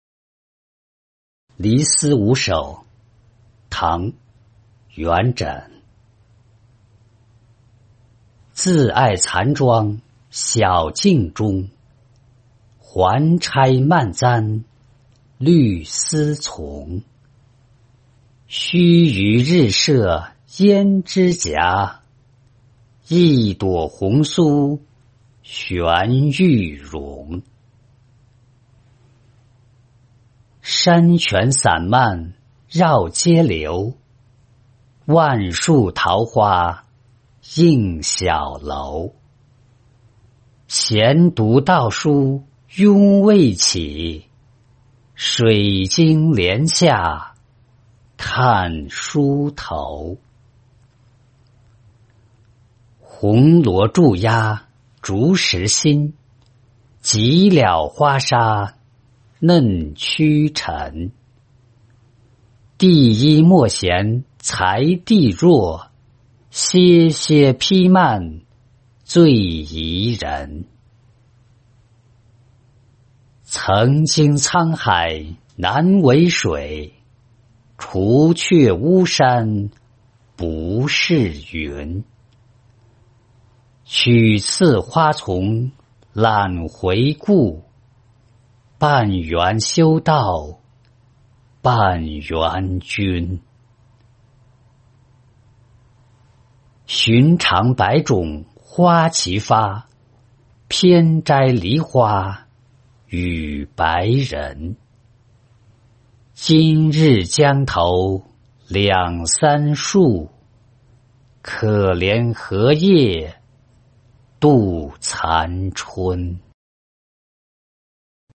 寒食野望吟-音频朗读